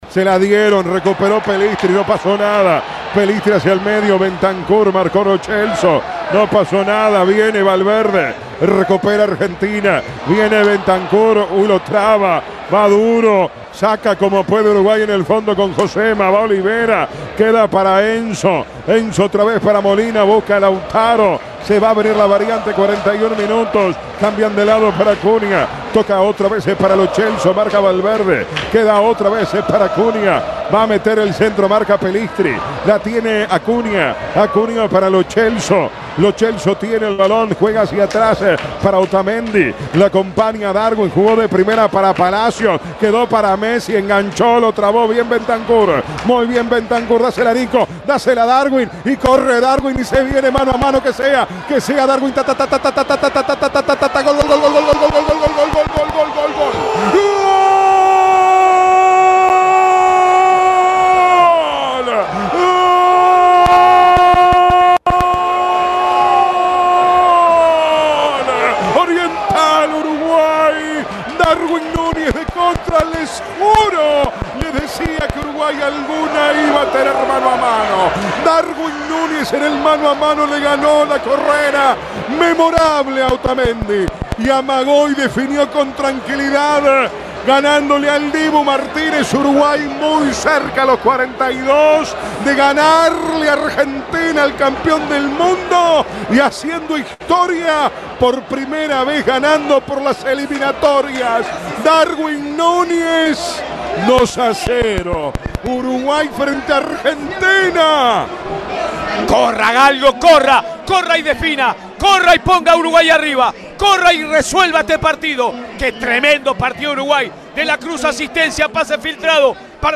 La histórica victoria celeste en la voz del equipo de Vamos que Vamos